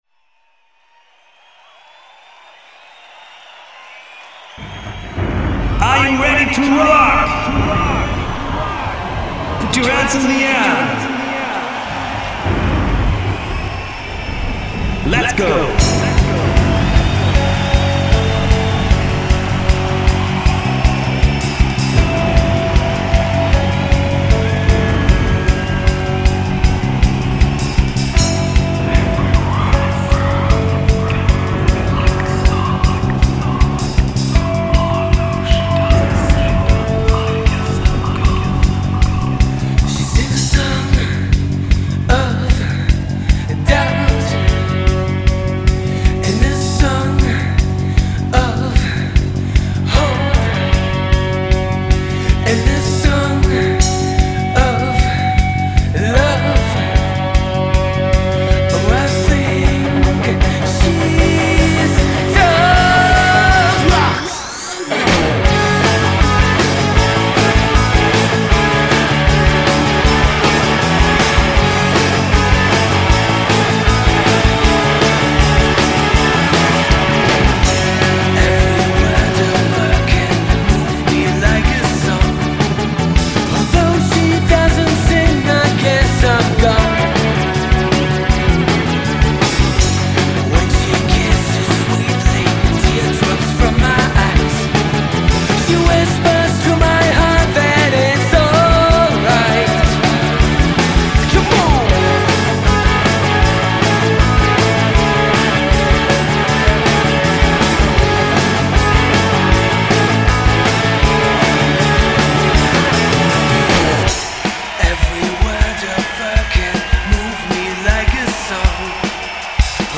Aufgenommen bei SAE in Hamburg.